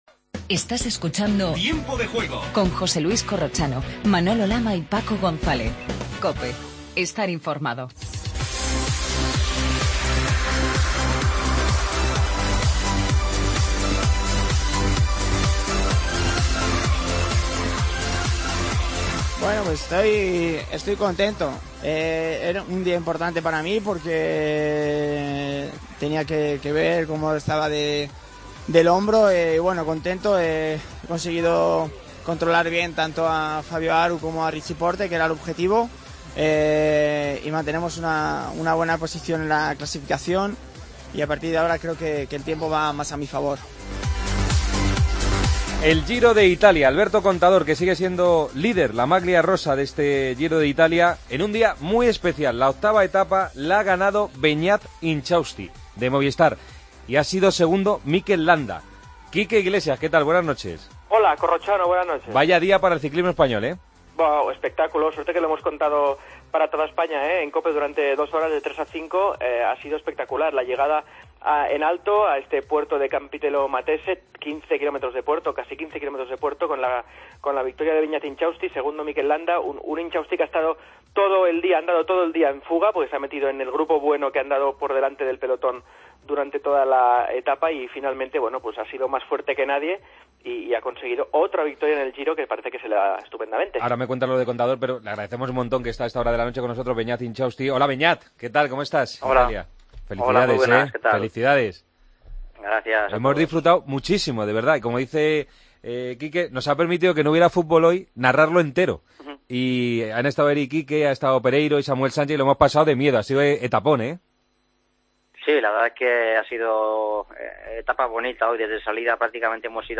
Entrevistas a Intxausti, ganador de la etapa de la 8ª etapa del Giro; y a Alex Rins, que nos habla del GP de Francia de motociclismo. La agenda del día.